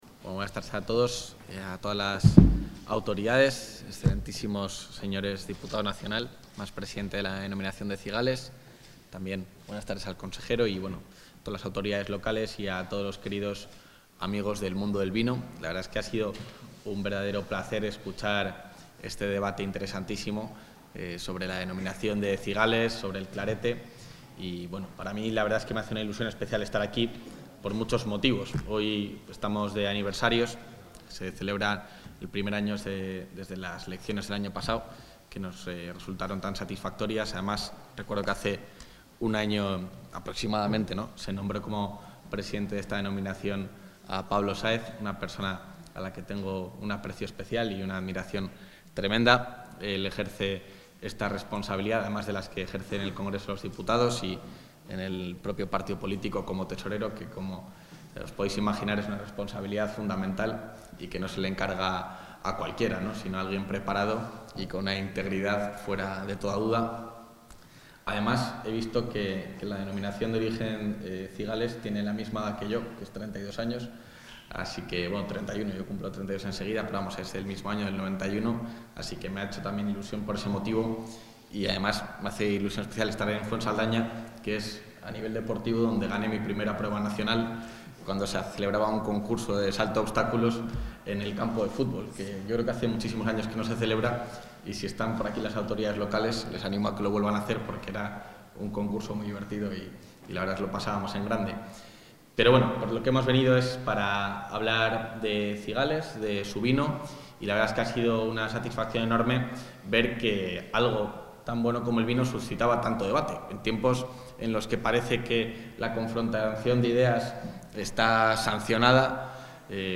El vicepresidente de la Junta de Castilla y León, Juan García-Gallardo, ha clausurado esta tarde en el castillo de Fuensaldaña...
Intervención del vicepresidente de la Junta.